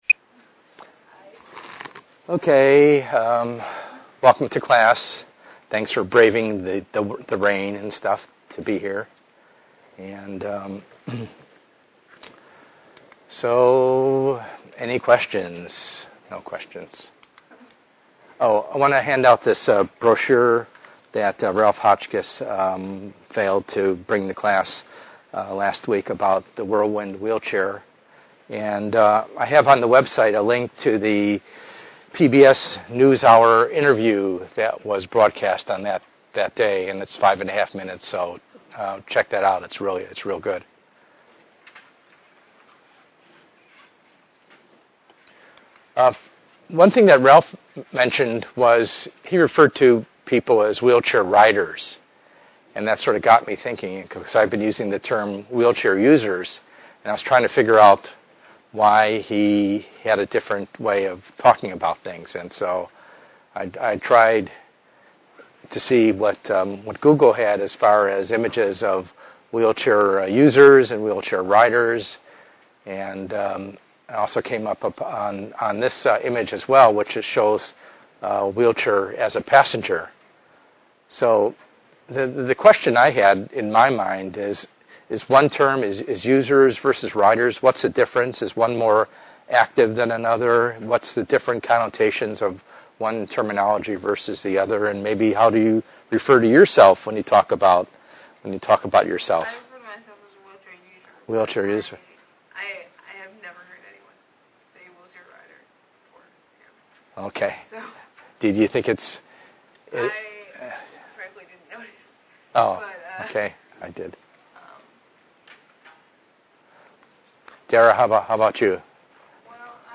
ENGR110/210: Perspectives in Assistive Technology - Lecture 8a